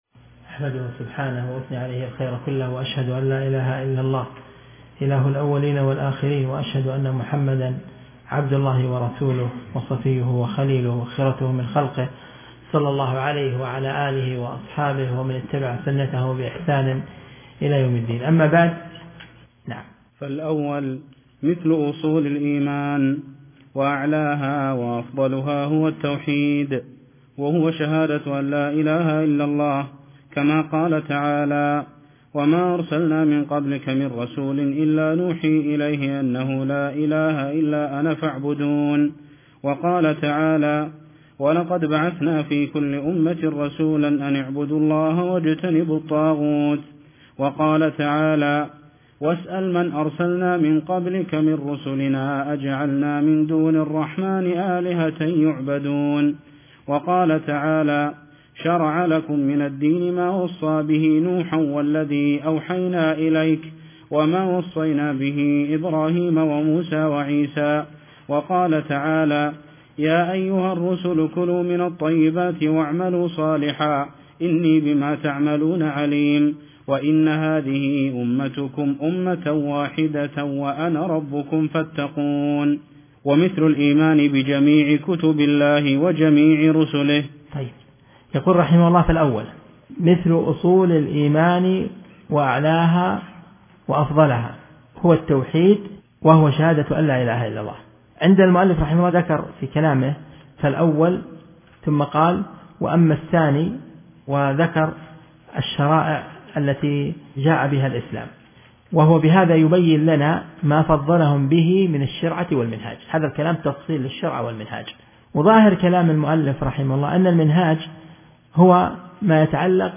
الدرس (2) من شرح رسالة الوصية الكبرى